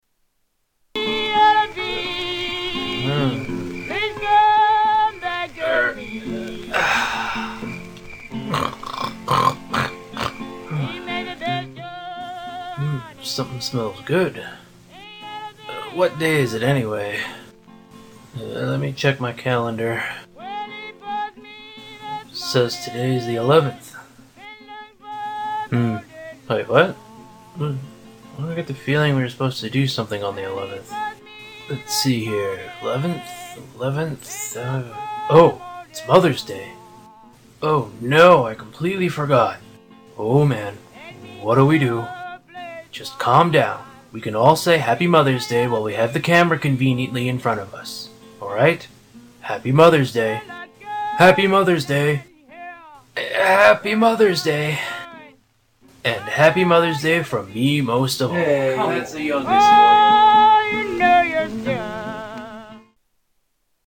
Cats with Very Similar-Sounding Voices